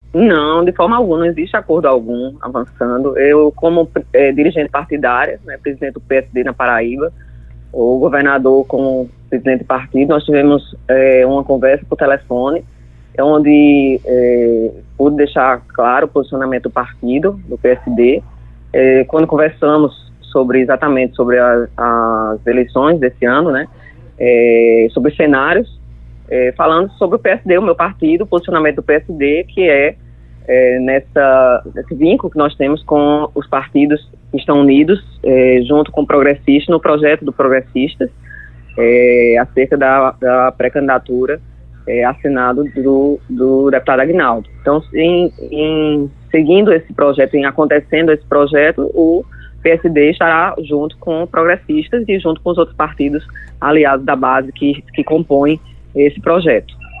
O comentário da senadora foi registrado nesta terça-feira (07/06), pelo programa Correio Debate, da 98 FM, de João Pessoa.